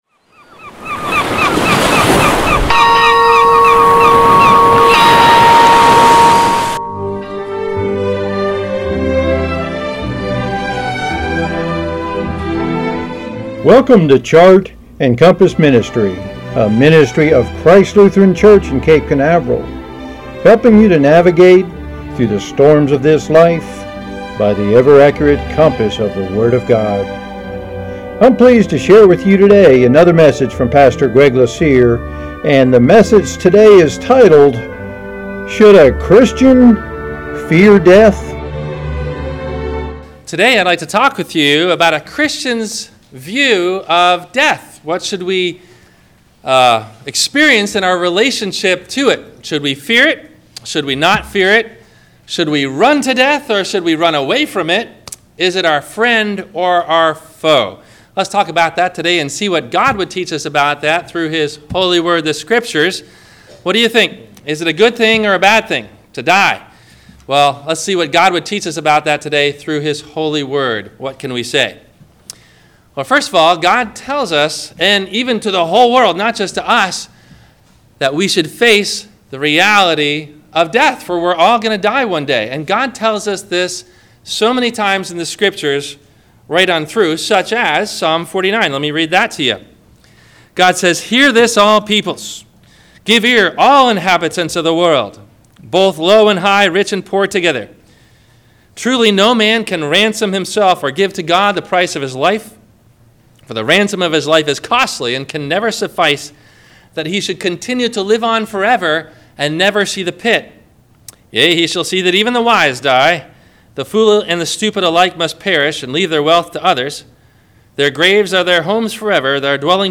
Children of Wrath or Children of God? – WMIE Radio Sermon – July 13 2020